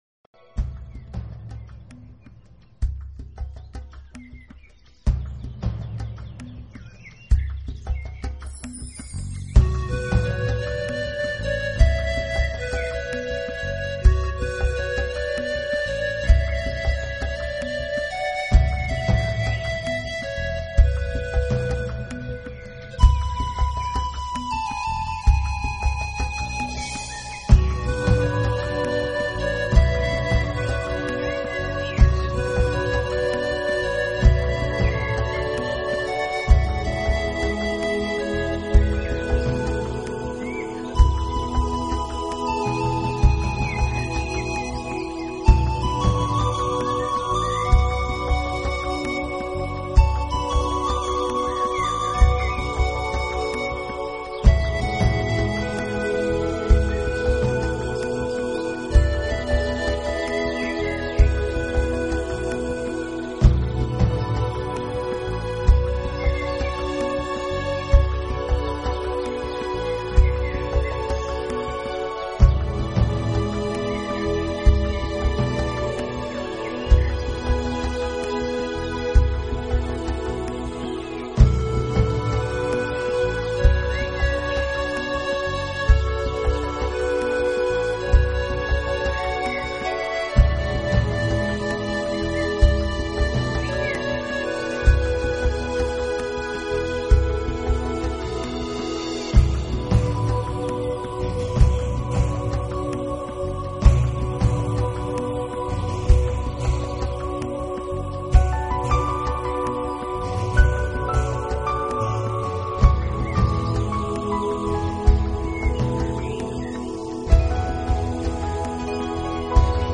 整张唱片是给人休息和少许的催眠功效。
排箫，钢琴，吉他，轻鼓还有奔腾的溪流……
随着平缓悦耳的排箫声，带着神秘的色彩，将你带入印加那片神秘的土地……